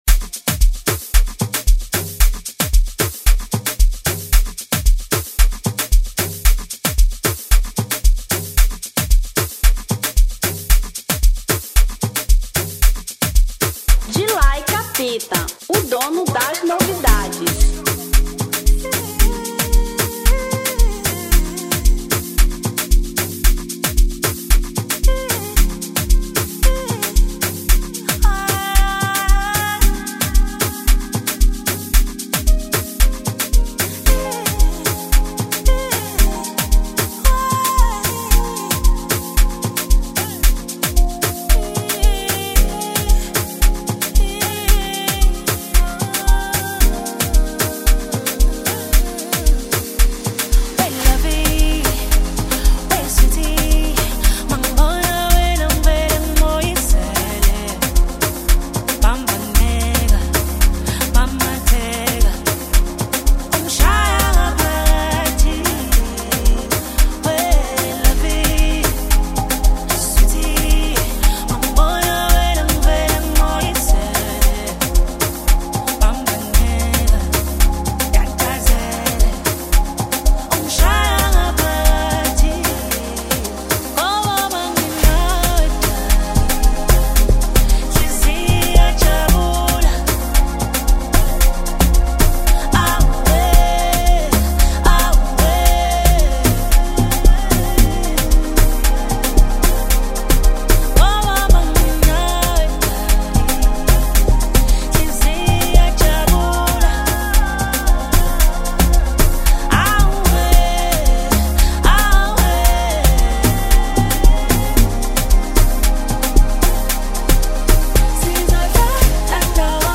Amapiano 2025